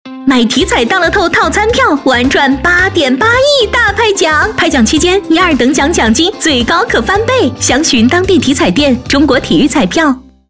2025体彩大乐透8.8亿派奖遇上套餐票10s- 女版